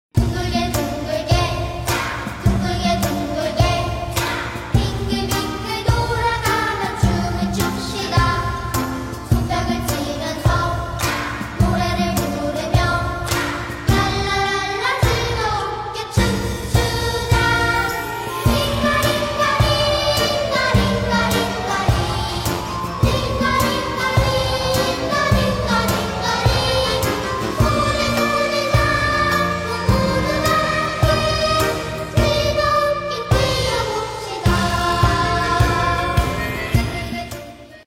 haunting and suspenseful tune